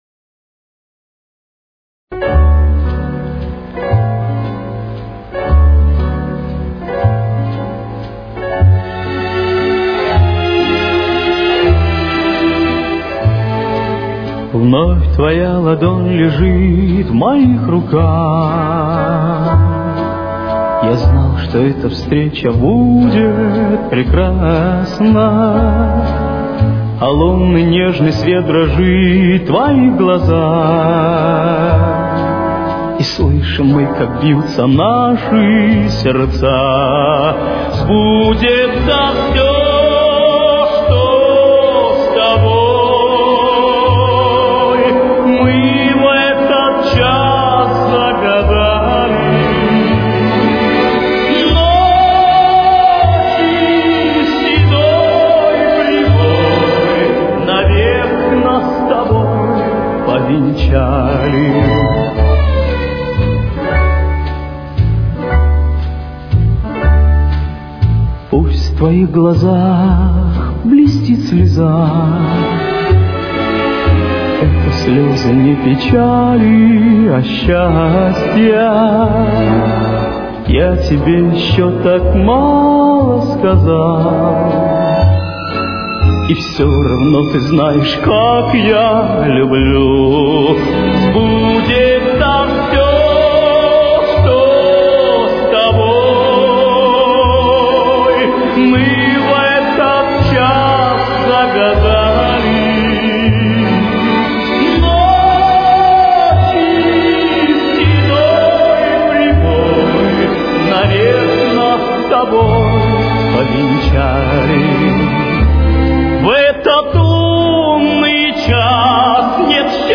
Темп: 120.